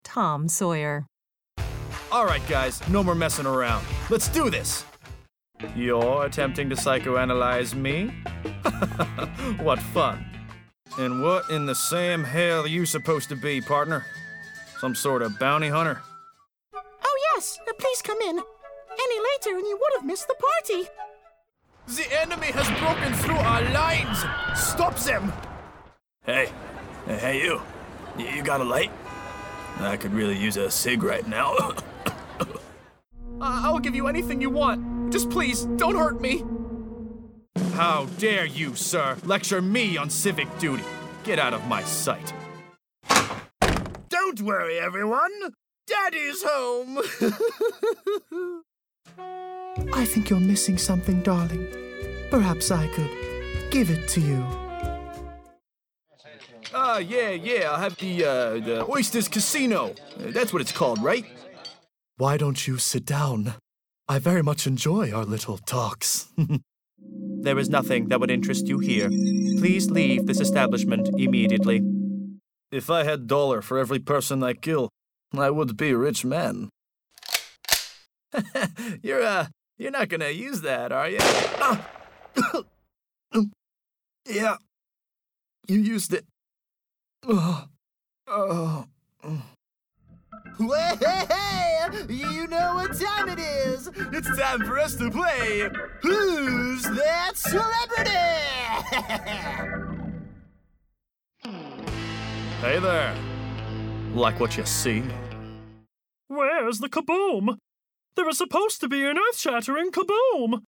Download Voiceover Reel